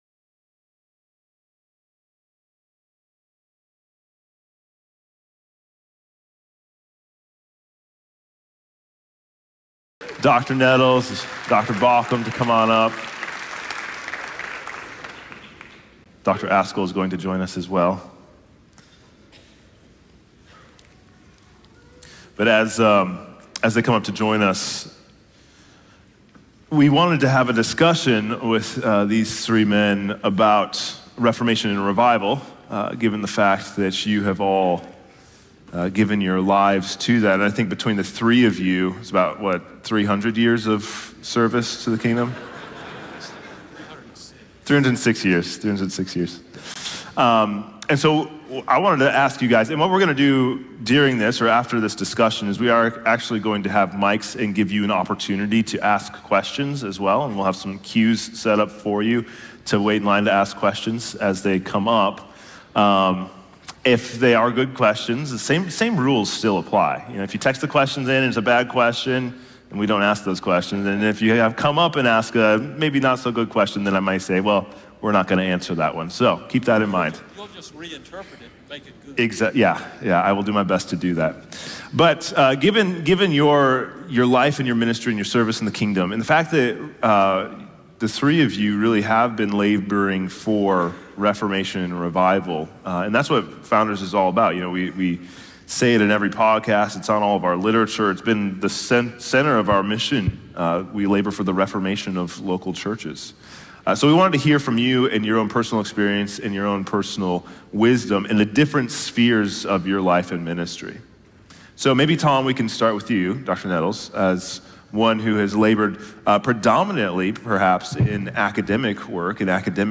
Founders Seminary & Q&A Panel Discussion